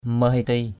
/mə-hi-ti:/ mahiti mh{t} [A, 389] (d.) sự cẩu thả, sự bất cẩn, bỏ bê = négligence, nonchalance, incurie, mollesse.